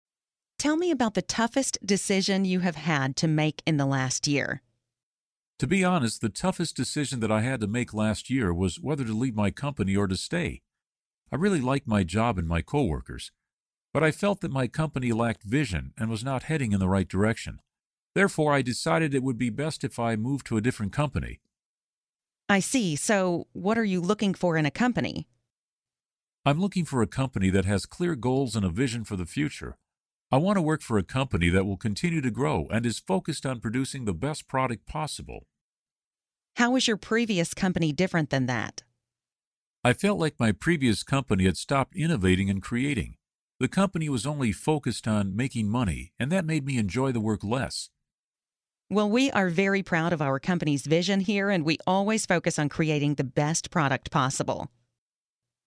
Learn different ways to answer the interview question 'Tell me about the toughest decision you have had to make in the last year.', listen to an example conversation, and study example sentences.